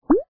背包-放置元素音效.mp3